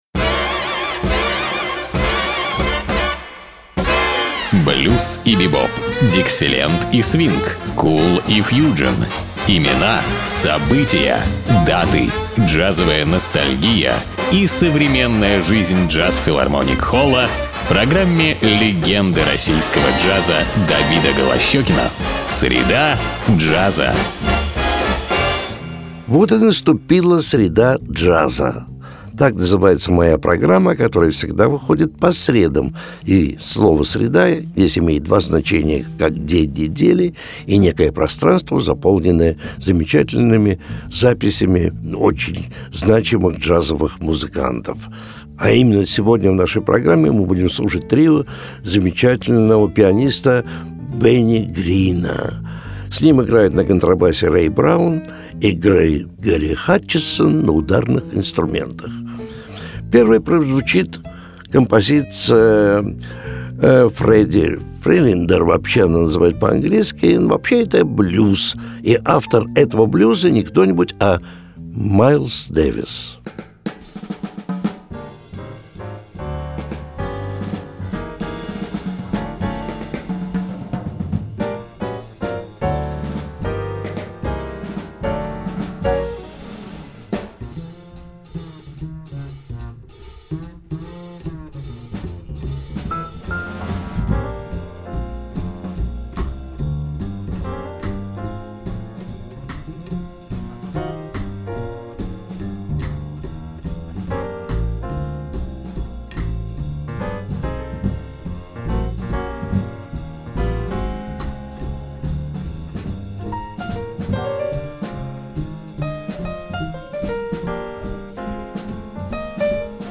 Массачусетс, Бостон, 1996 год.